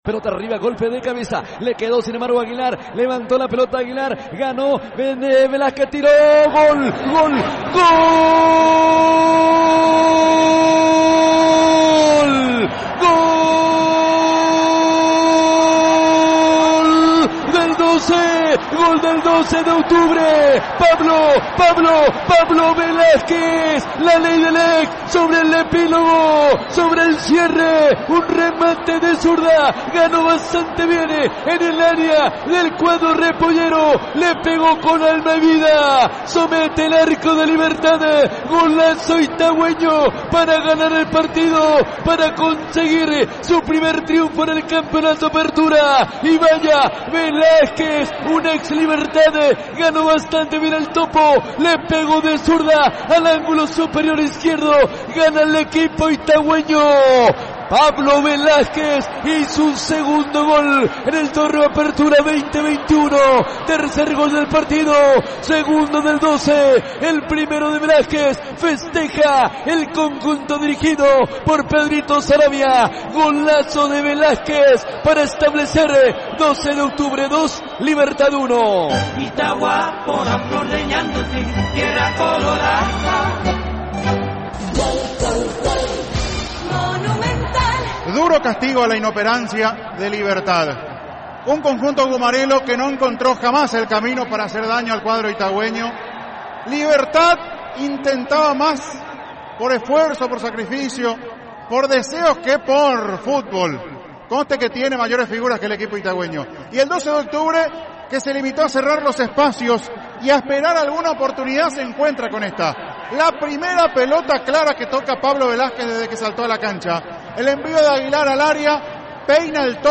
Relatos y comentarios del equipo de FALG.